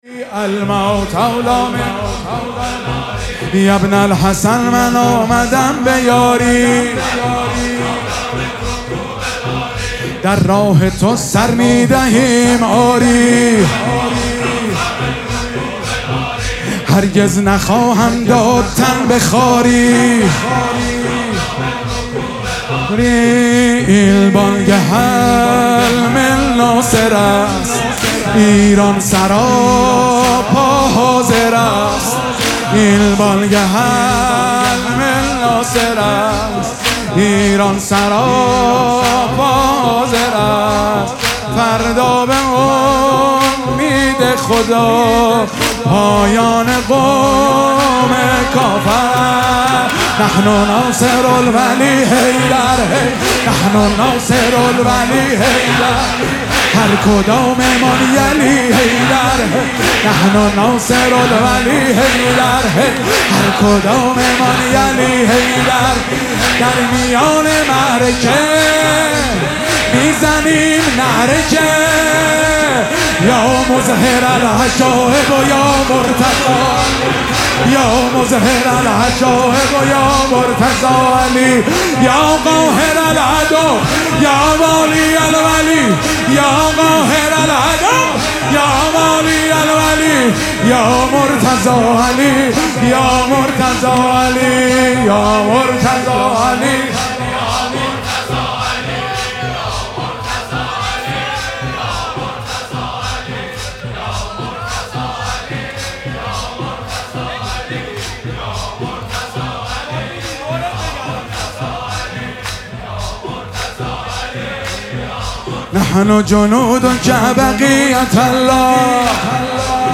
حماسی خوانی مداحان برای ایران/ "اینجا ایران امام حسینه"